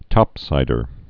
(tŏpsīdər)